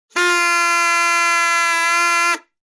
Descarga de Sonidos mp3 Gratis: bocina 7.